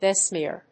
be・smear /bɪsmíɚ‐smíə/
発音記号
• / bɪsmíɚ(米国英語)
• / bɪsmíə(英国英語)